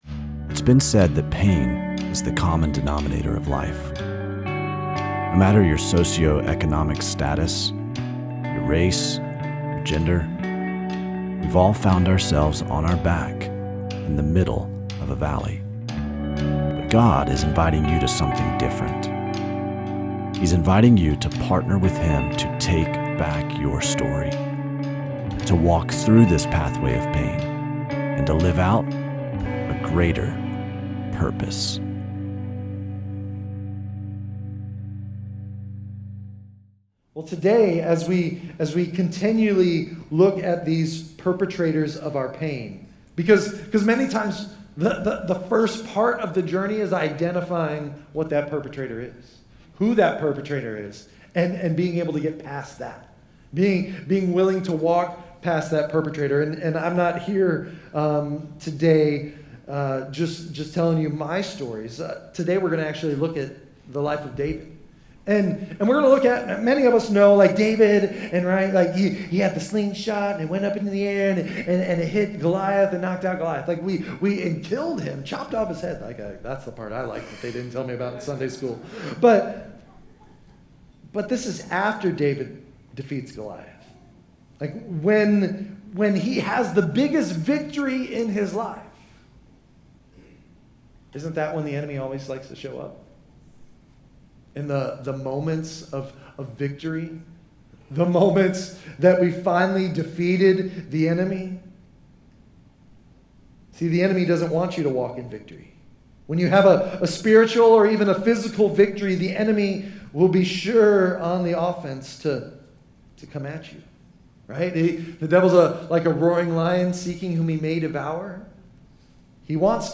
This sermon dives into 1 Samuel 18 & 26, showing how David refused to return offense with offense.